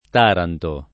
Taranto [ t # ranto ]